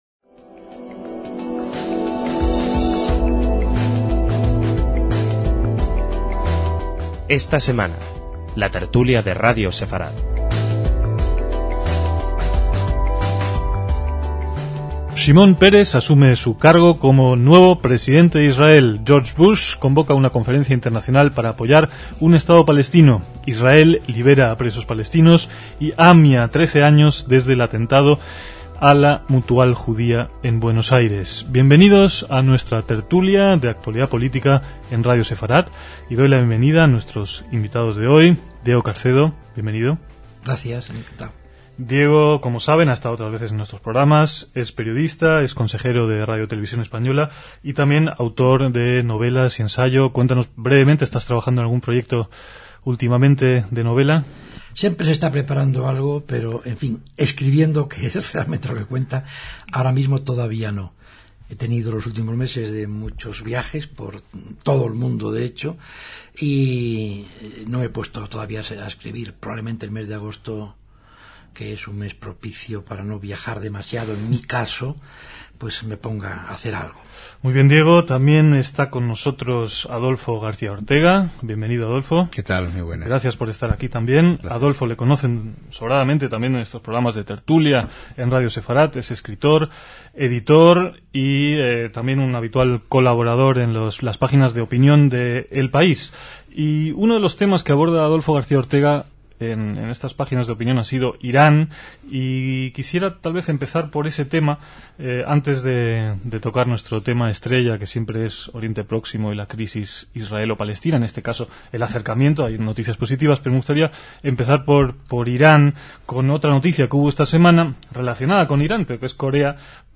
DECÍAMOS AYER (21/7/2007) - Las iniciativas de unas negociaciones para la paz con los palestinos parecían irradiar cierta esperanza a mediados de 2007, y ello fue el tema central de los contertulios Diego Carcedo y Adolfo García Ortega.